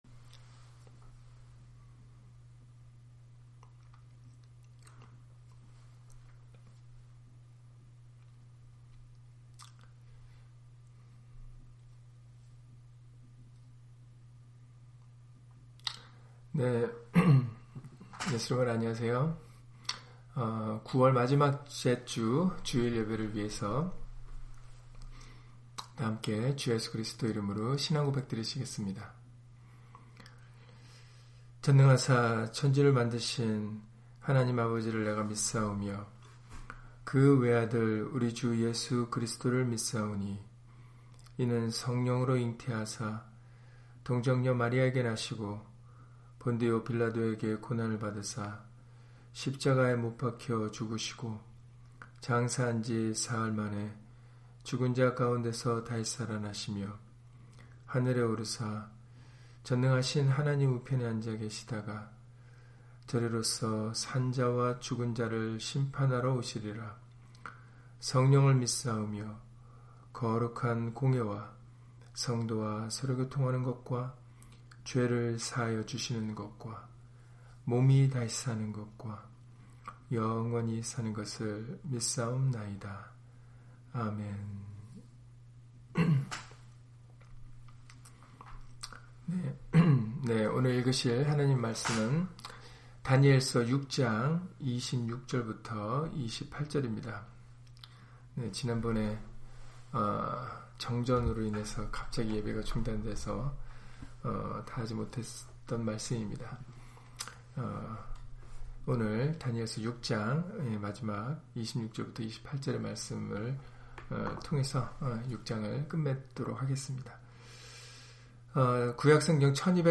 다니엘 6장 26-28절 [다 하나님 앞에서 떨며 두려워할찌니] - 주일/수요예배 설교 - 주 예수 그리스도 이름 예배당